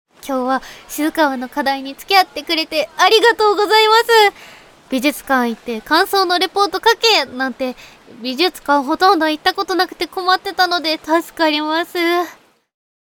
ハコネクト所属メンバーが収録した「夏のおでかけ」をテーマにしたコンセプトボイスを是非お楽しみください！
ボイスサンプル